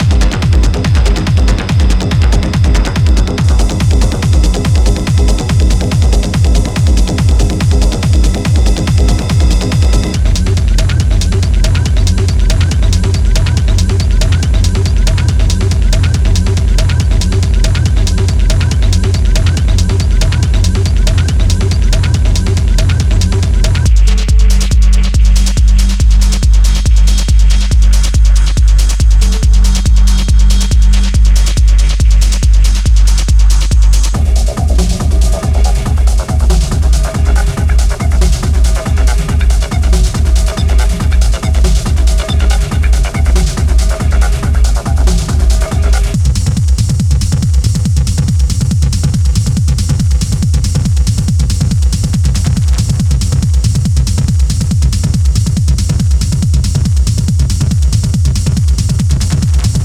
Are you into raw and hypnotic techno and looking for the right sounds?
The sounds are organised thematically in practical kits so that you can create different hi-hat, percussion, kick or shaker grooves in no time at all.
Genre / Style: Raw / Hypnotic
Sound category: Bass, FX, HiHat, Kick, Percussion, Ride, Synth